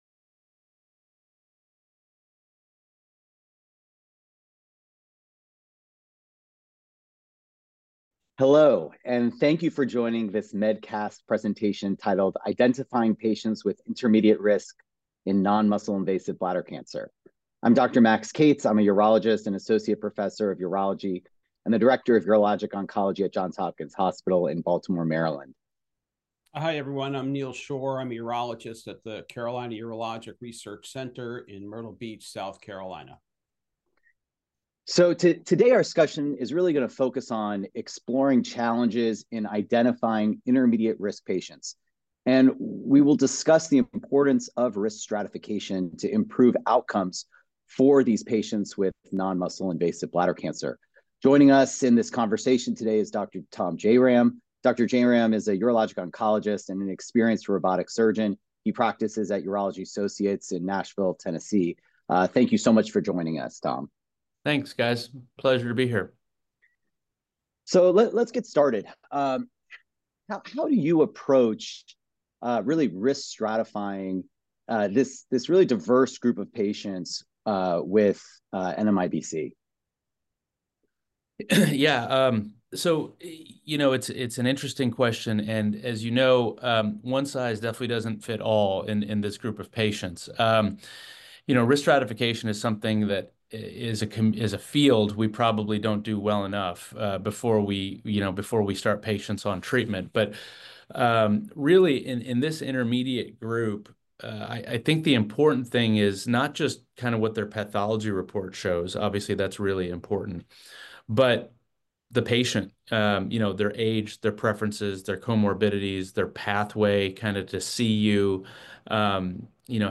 Panelists explore challenges in identification of intermediate risk patients and emphasize importance of risk stratification to improve outcomes in regards to NMIBC.